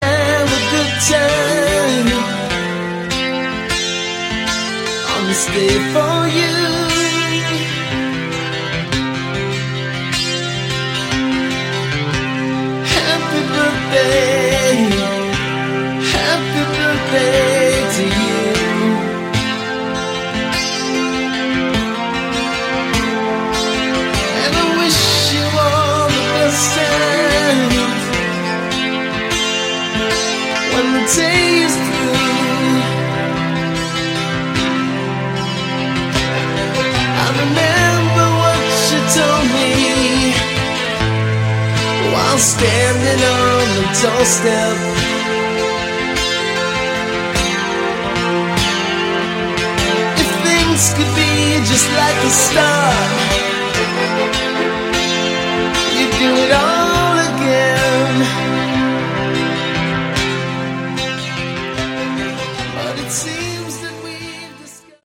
Category: Hard Rock
vocals, guitar, keyboards, bass
drums